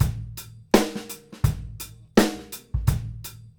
GROOVE 110AR.wav